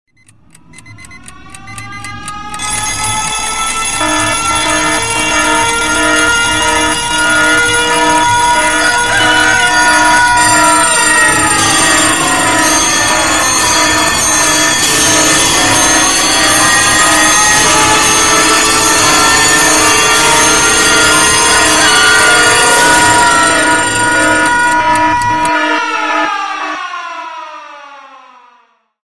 Рингтоны для будильника